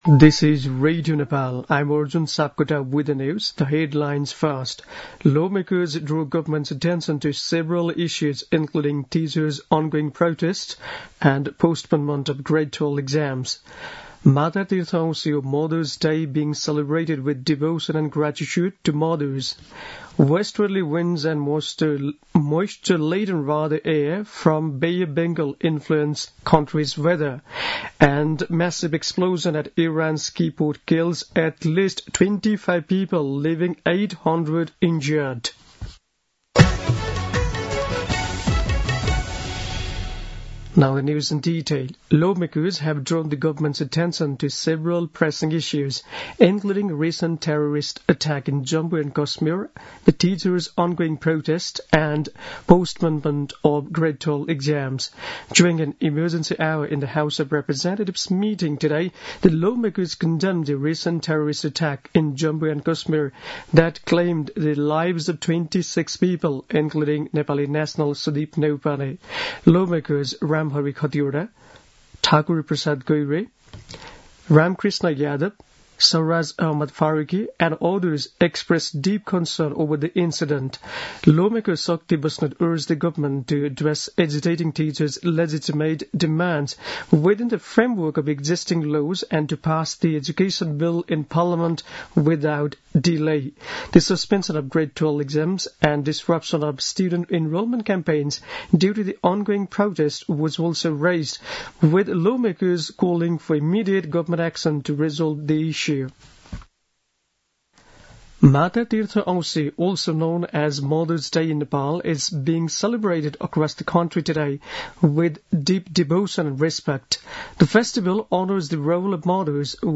दिउँसो २ बजेको अङ्ग्रेजी समाचार : १४ वैशाख , २०८२